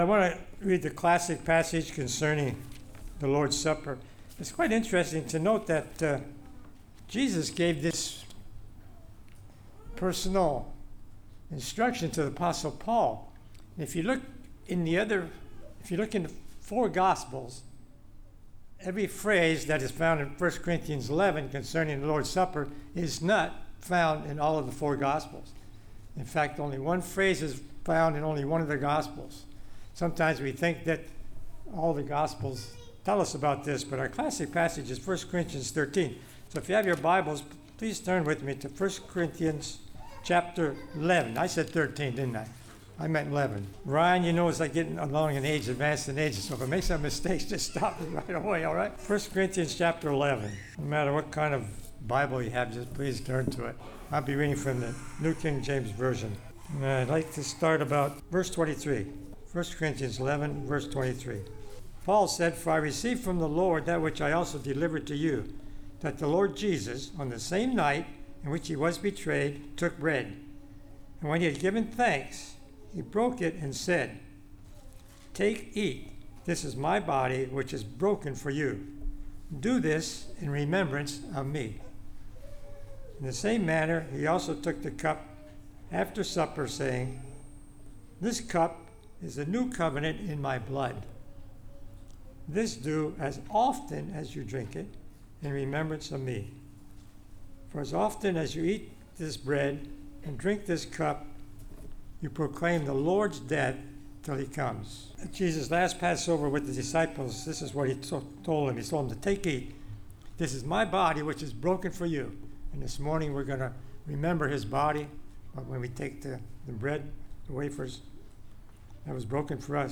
2017 Sermons - Grace Bible Church of Port Charlotte, Florida